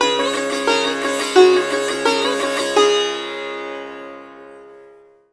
SITAR GRV 04.wav